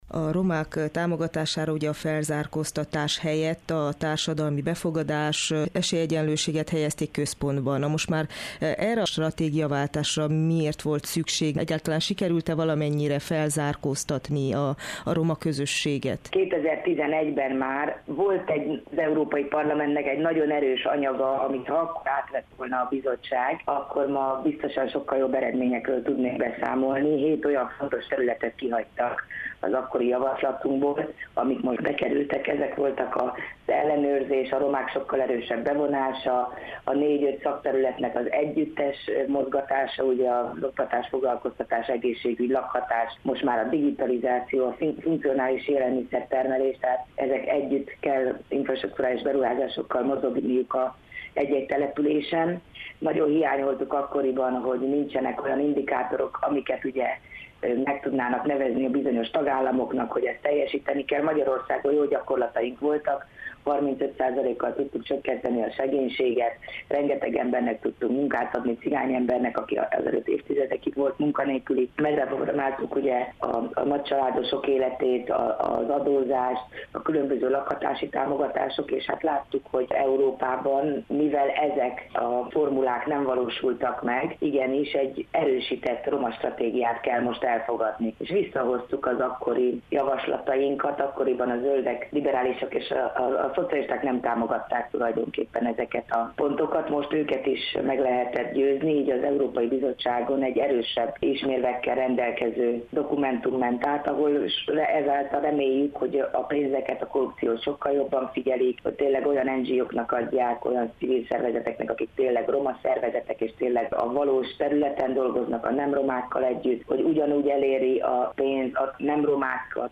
EP képviselőt kérdezte